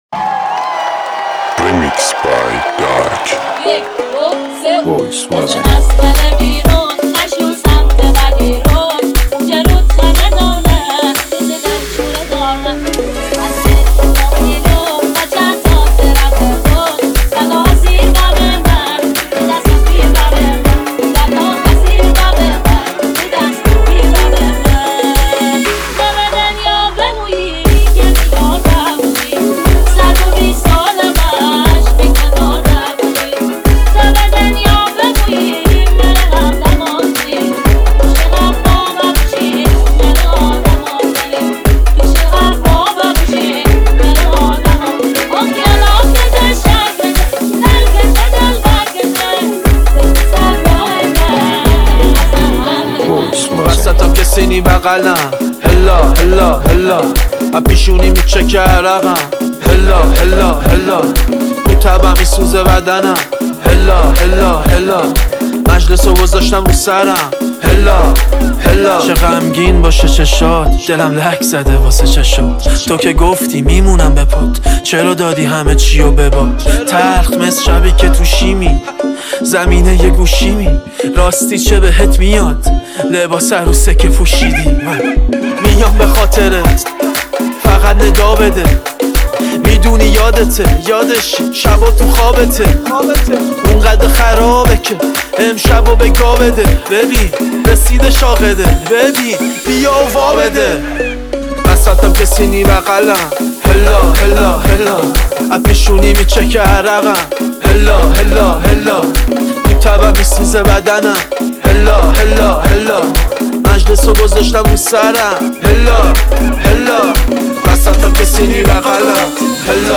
Remix Trend Instagram